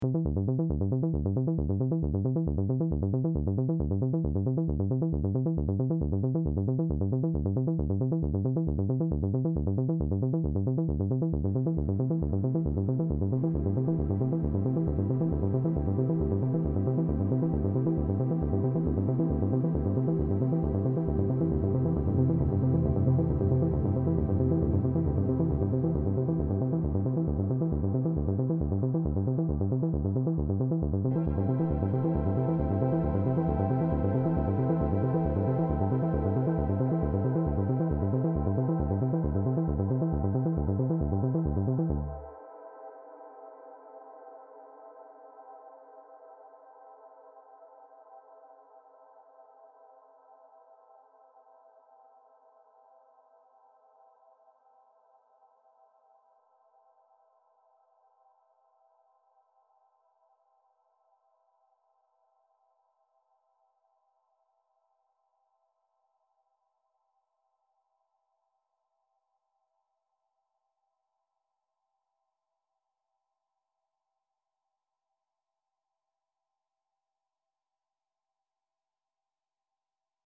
Juipter XP, tenet soundtrack by accident